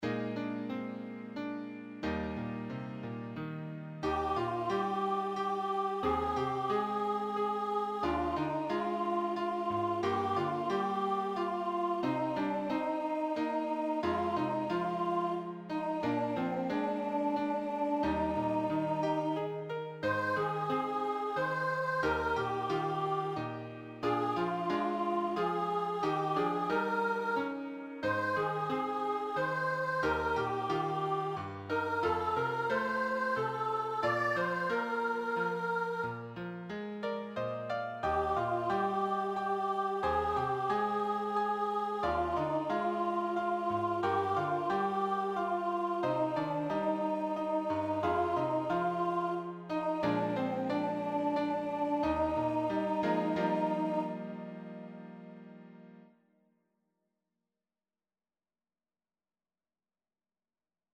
A collection of 15 uplifting and Christ-glorifying children’s choruses covering a wide range of Biblical topics.